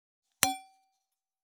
2025年2月14日 / 最終更新日時 : 2025年2月14日 cross 効果音
307シャンパングラス,ウィスキーグラス,ヴィンテージ,ステンレス,金物グラス,
効果音厨房/台所/レストラン/kitchen食器